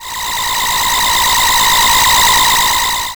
ComputerHack.wav